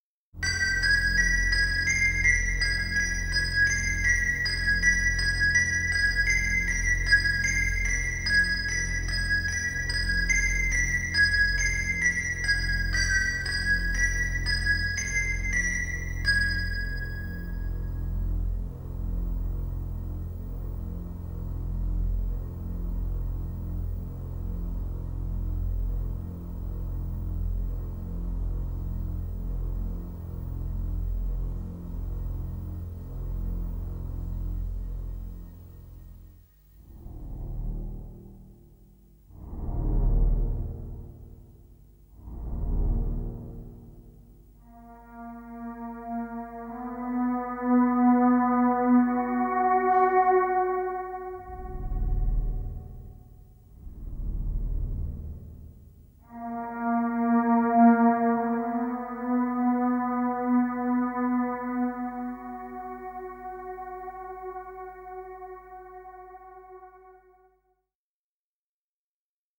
ELECTRONIC CUES